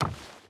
Footsteps / Wood / Wood Run 5.ogg
Wood Run 5.ogg